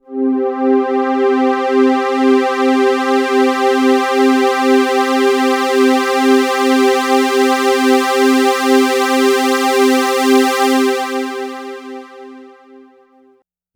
Synth Pad.wav